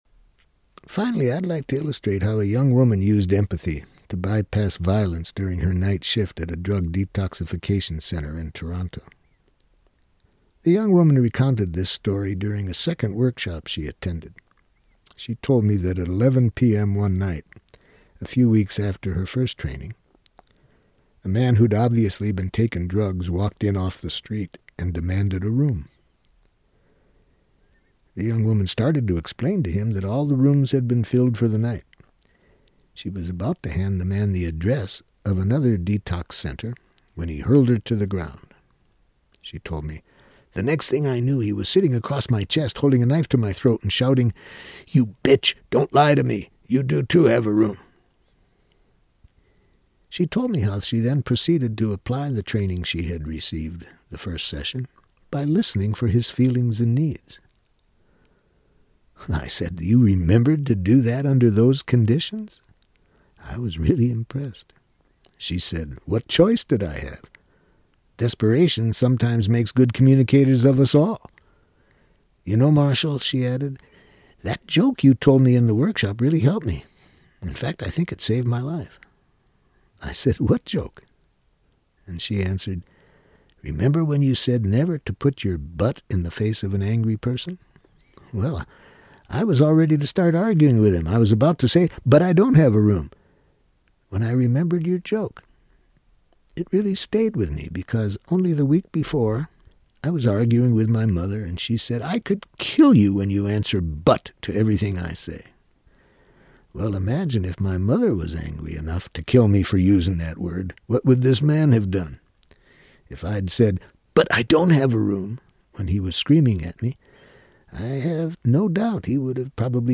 On Nonviolent Communication, this renowned peacemaker presents his complete system for speaking our deepest truths, addressing our unrecognized needs and emotions, and honoring those same concerns in others. With this adaptation of the bestselling book of the same title, Marshall Rosenberg teaches his proven methods for resolving the unresolvable in his own words. 4 CDs.